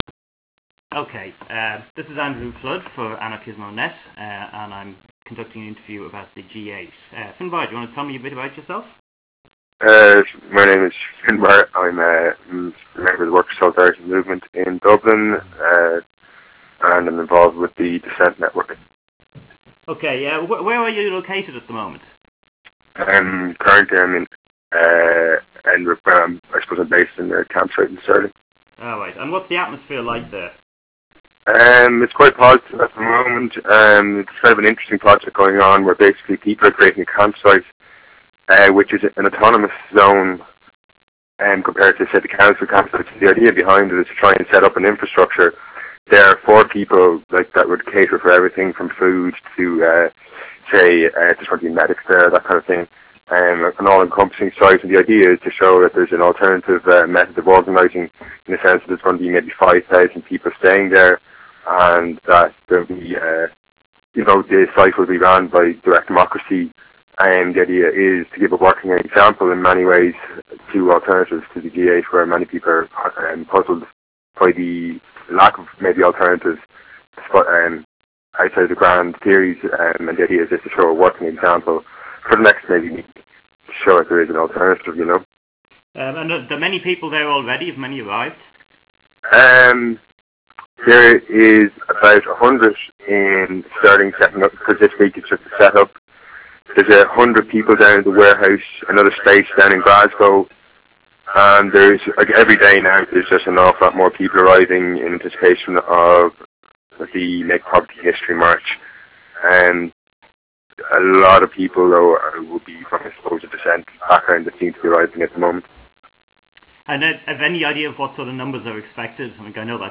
Interview with Dissent participant in Edinbrugh